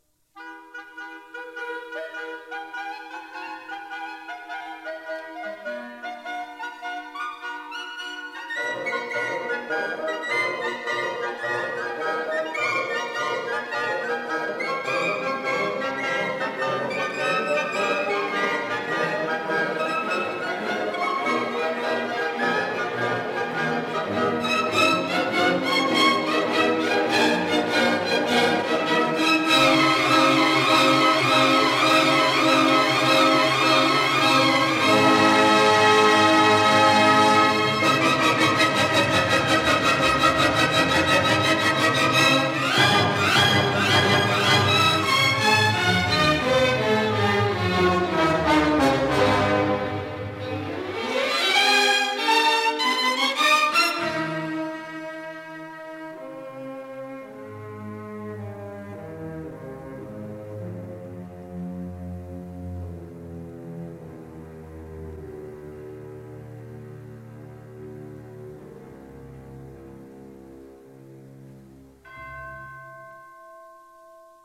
conductor
1958 stereo recording